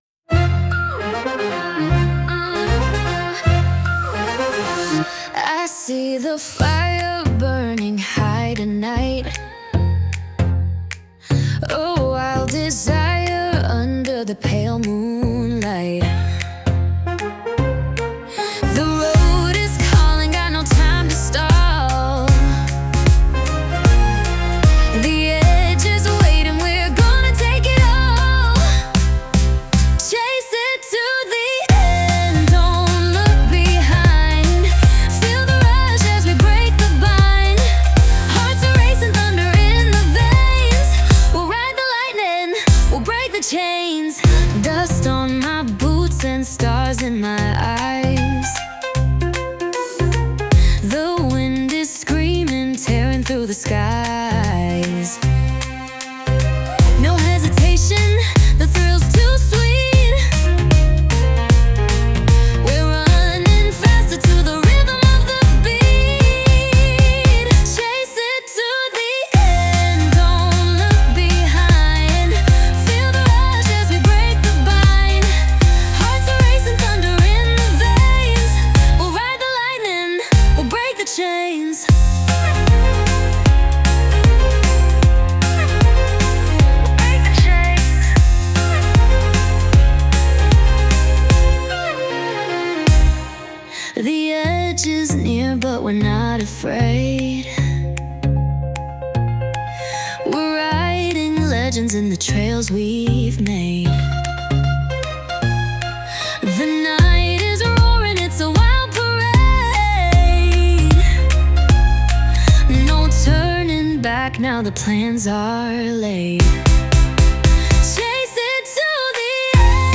With Vocals / 歌あり
前向きなエネルギーがぎゅっと詰まった、歌ありの明るく踊りやすい一曲。
テンポも使いやすく、ジュニアの選手でも表現しやすい仕上がり。